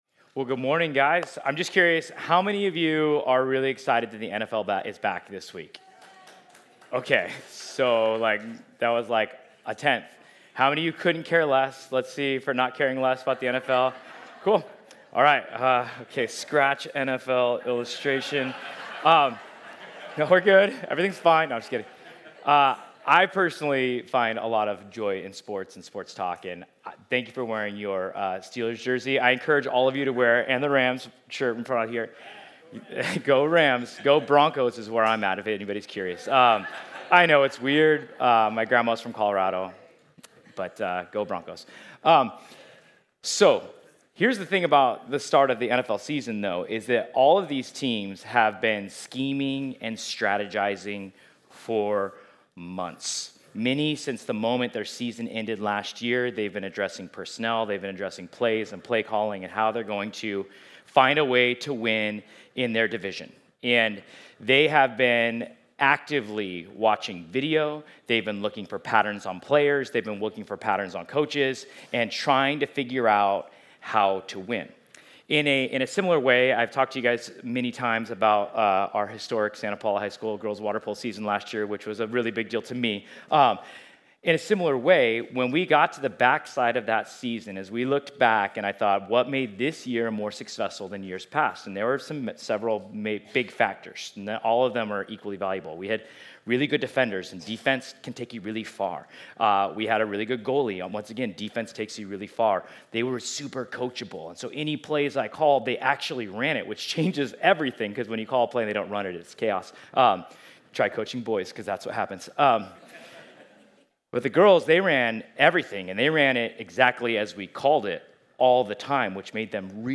Ephesians 6:10-18 Service Type: Sunday Is the devil really real?